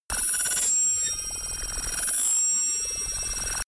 Royalty free sounds: Pulsation